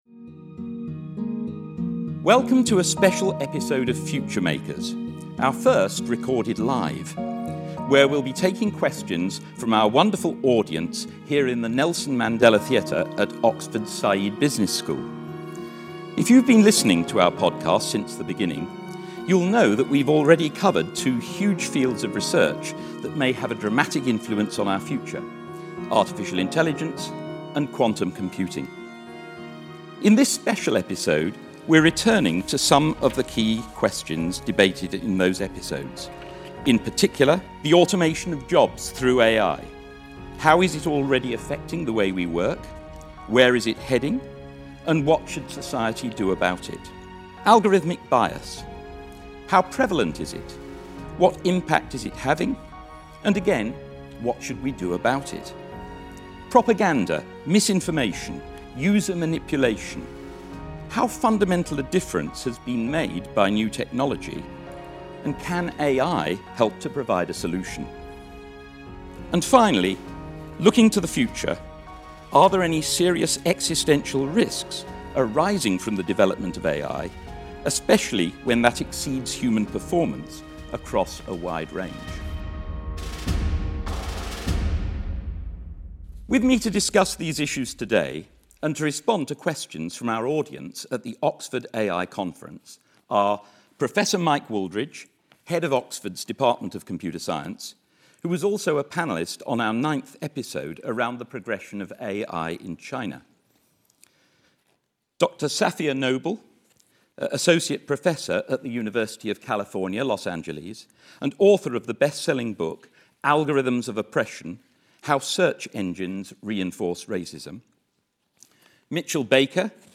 S2 Ep15: Live Special: Artificial Intelligence Q&A